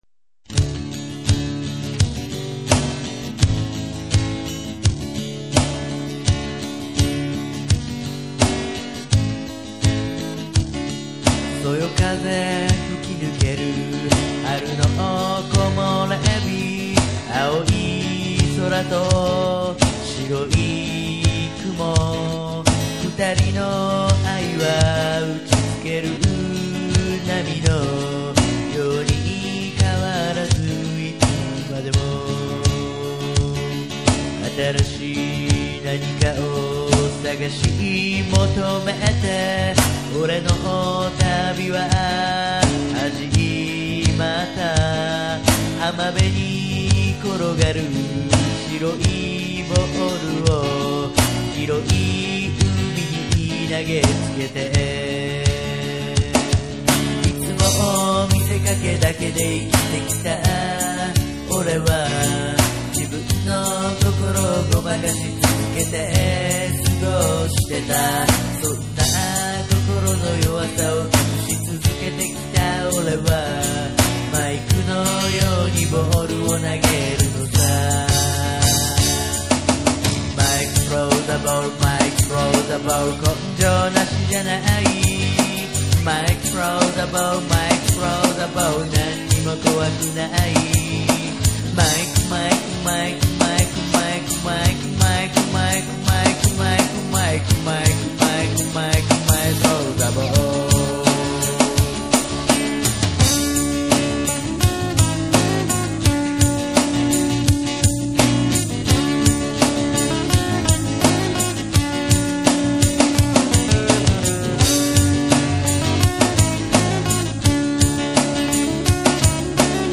リミックスバージョンには２番（というかギターソロとサビのリピート）がつきました。
原曲に忠実に、ドラムやギターを増やしリッチにしてみました。
タテにのれる、オモテ打ちのリズムとシンコペーション。